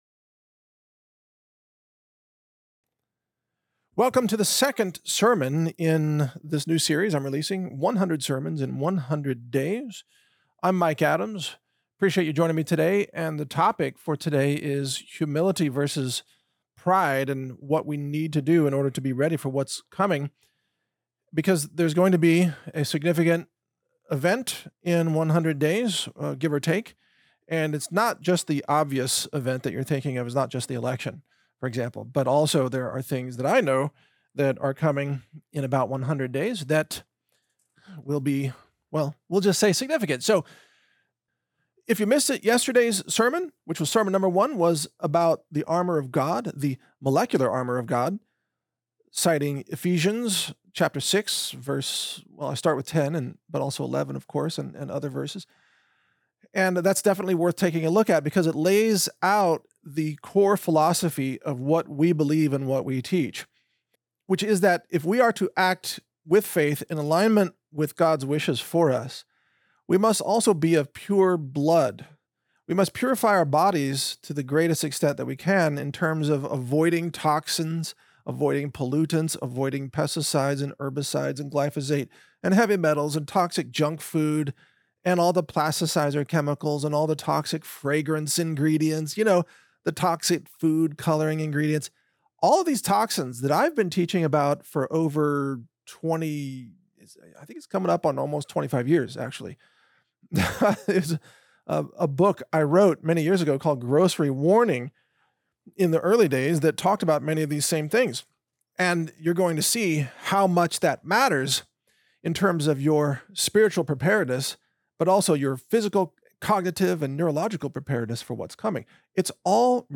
Mike Adams sermon 002 – Proverbs 11, wisdom earned through HUMILITY, and the earning of God's favor - Natural News Radio